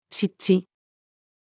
湿地-female.mp3